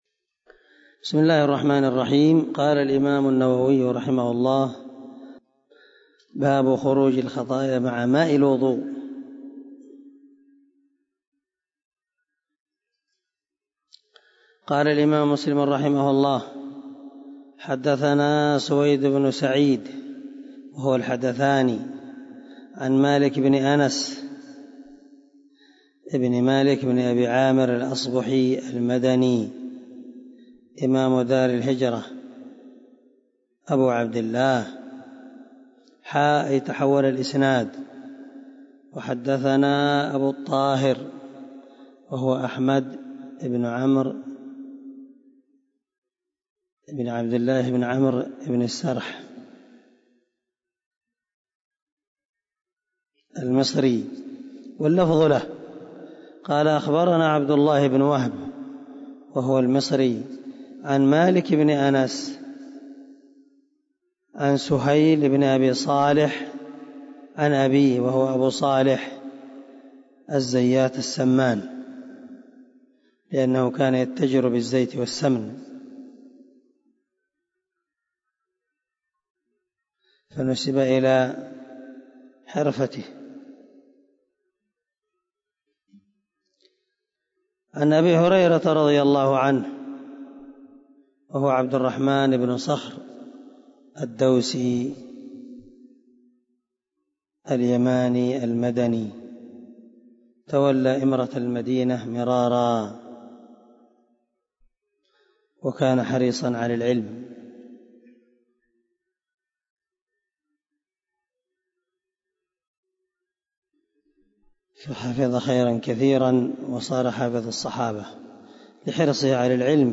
184الدرس 12 من شرح كتاب الطهارة حديث رقم ( 244 – 245 ) من صحيح مسلم